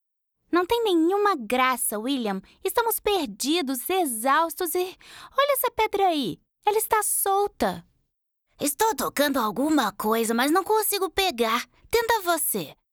Portugais (Brésil)
Commerciale, Jeune, Douce